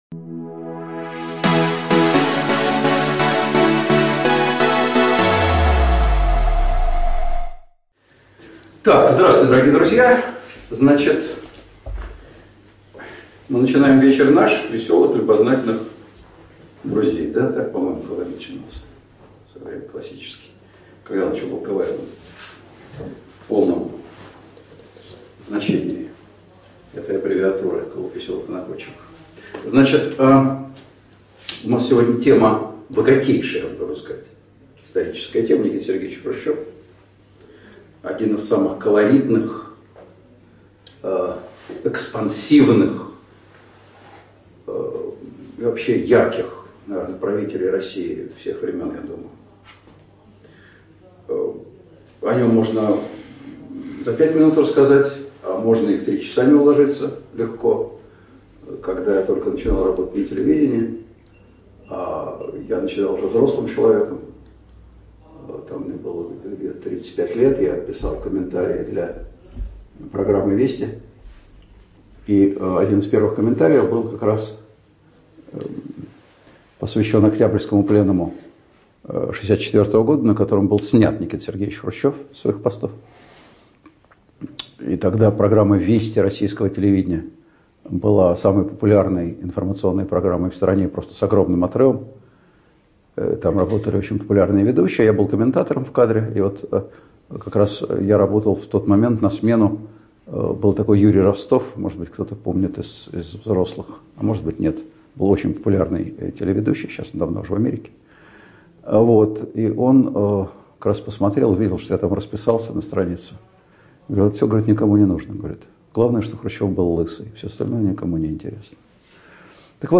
ЧитаетАвтор